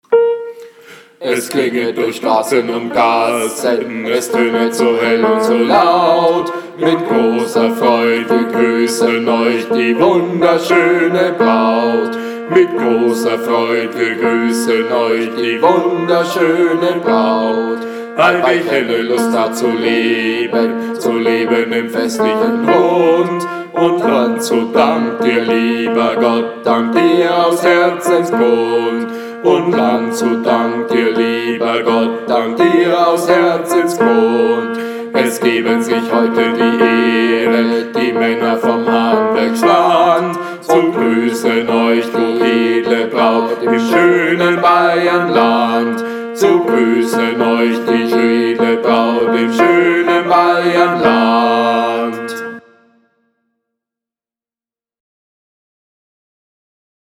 45 Willkommensgruss BASS.mp3